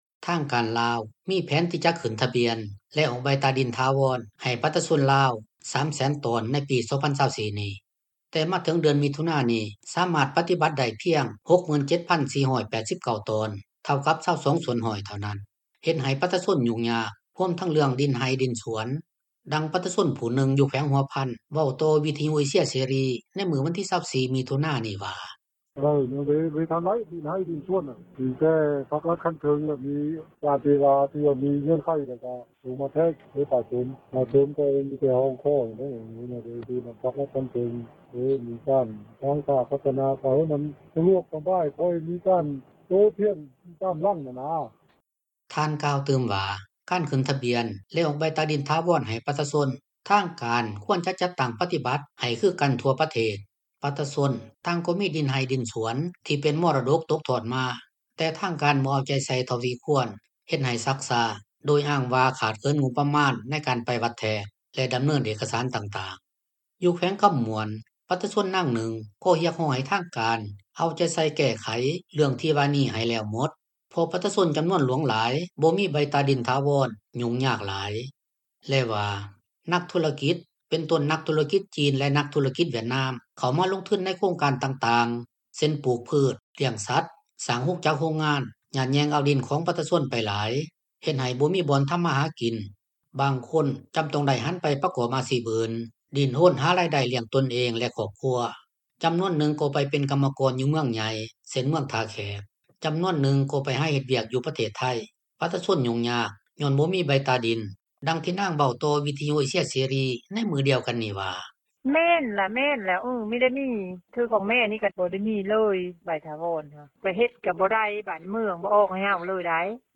ດັ່ງປະຊາຊົນຜູ້ໜຶ່ງ ຢູ່ແຂວງຫົວພັນ ເວົ້າຕໍ່ວິທຍຸເອເຊັຽເສຣີ ໃນມື້ວັນທີ 24 ມິຖຸນານີ້ວ່າ:
ດັ່ງທີ່ນາງເວົ້າຕໍ່ວິທຍຸເອເຊັຽເສຣີ ໃນມື້ດຽວກັນນີ້ວ່າ: